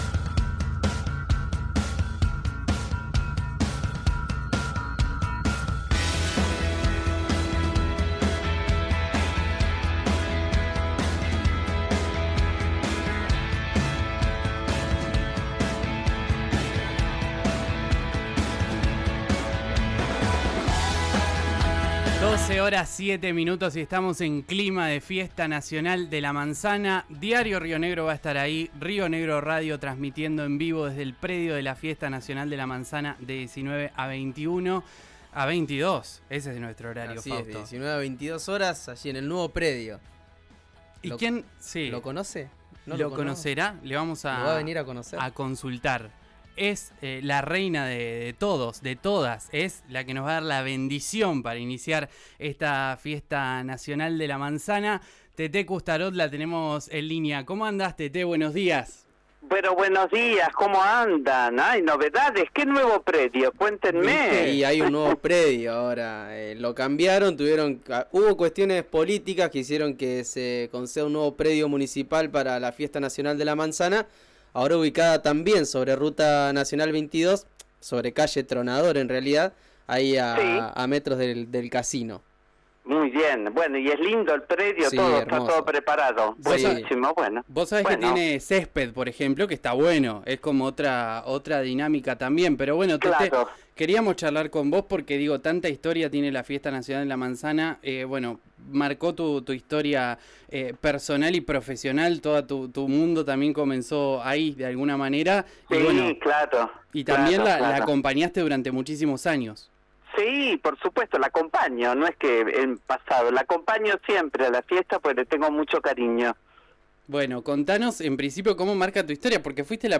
En ese contexto, Teté Coustarot habló con «Pará un poco«, en RÍO NEGRO RADIO, donde aseguró que tiene «una relación directa con la fruta», que ha marcado su historia de vida personal.
Escuchá a Teté Coustarot en RÍO NEGRO RADIO: